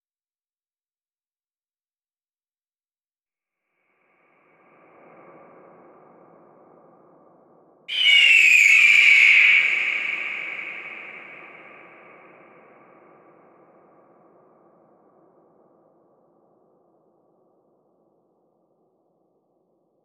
Звуки крика орла
На этой странице собраны звуки криков орлов – от пронзительных охотничьих кличей до переклички в полете.